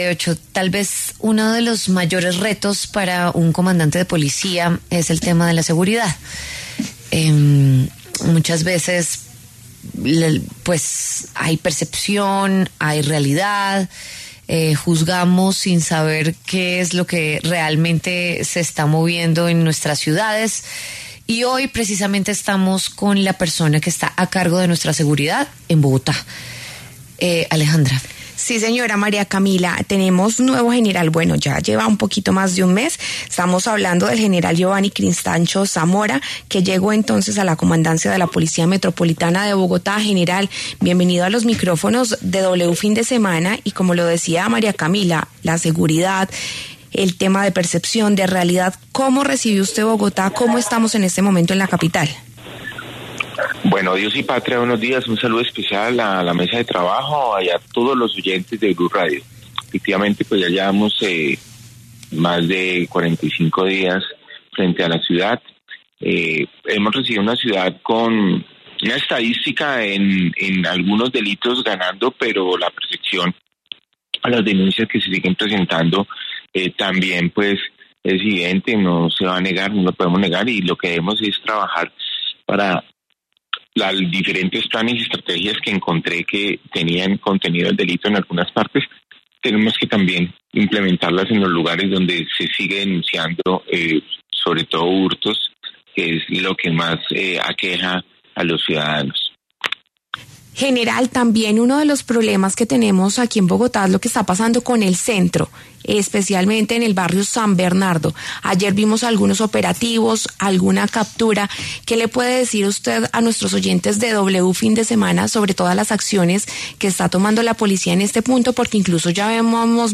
El comandante de la Policía Metropolitana de Bogotá Brigadier general Giovanni Cristancho Zambrano, entregó detalles de la situación de delincuencia en San Bernardo.